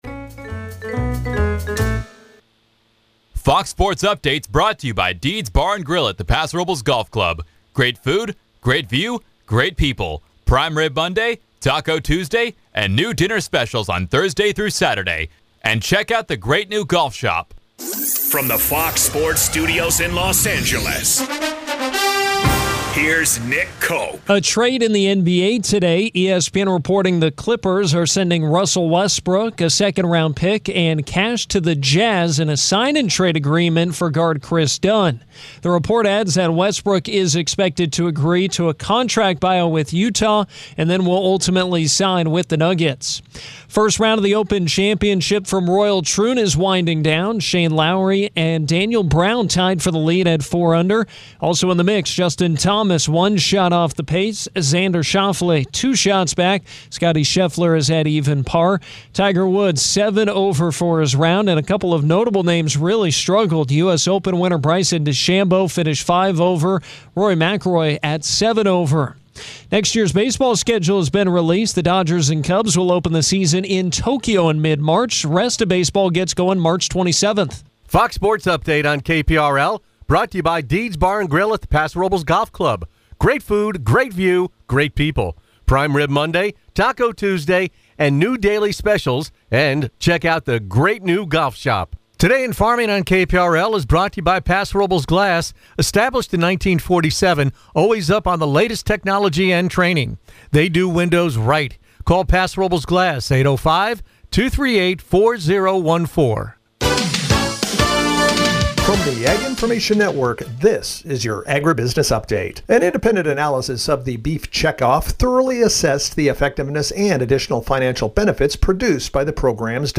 Tune in to the longest running talk show on the Central Coast – now in its sixth decade.